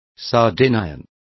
Also find out how sardos is pronounced correctly.